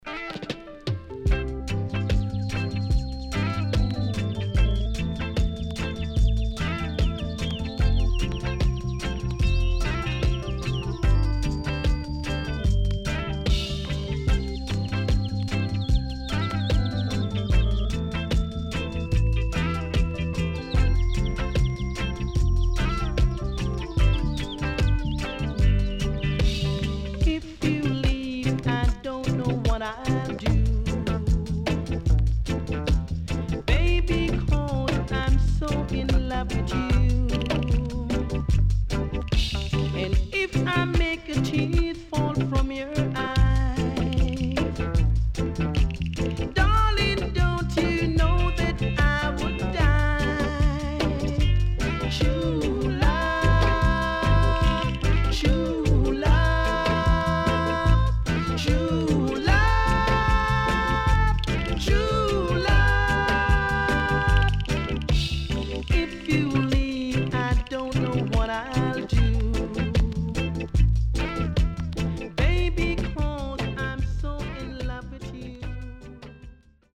SIDE A:所々チリノイズがあり、少しプチノイズ入ります。
SIDE B:所々チリノイズがあり、少しプチノイズ入ります。